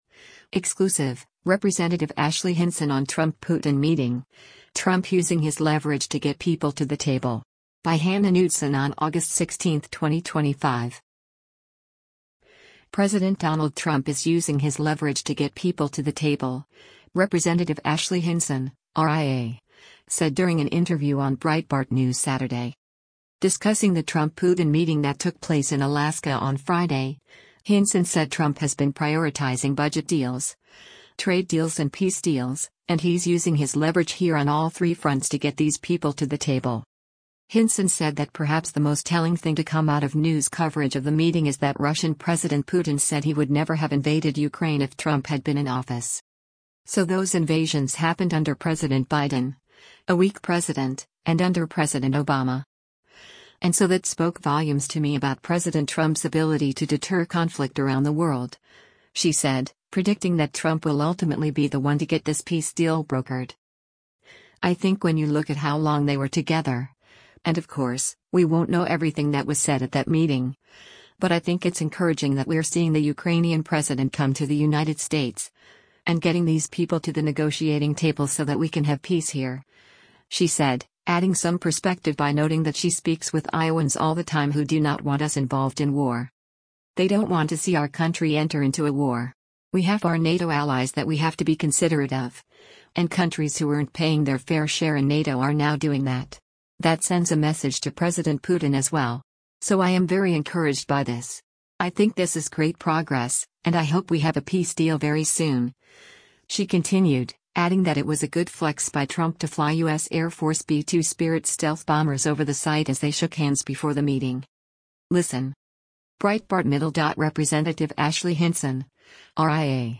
President Donald Trump is using his leverage to get people to the table, Rep. Ashley Hinson (R-IA) said during an interview on Breitbart News Saturday.